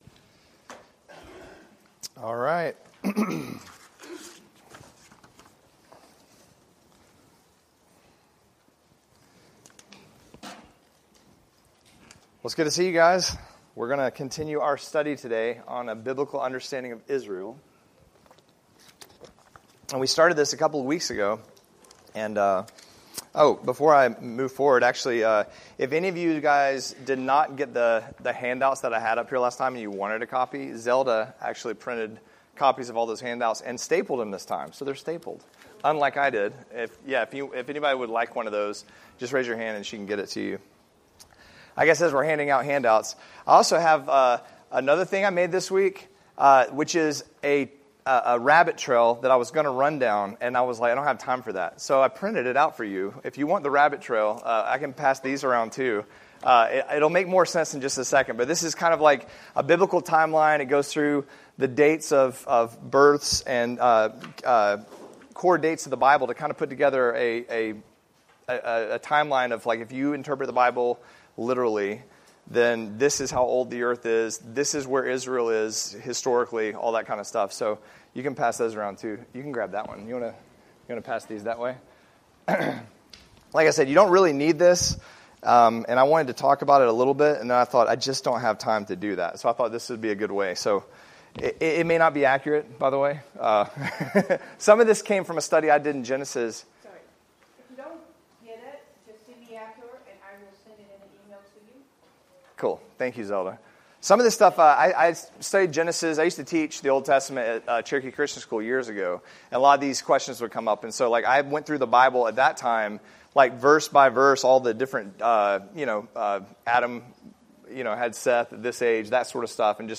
Adult Bible Study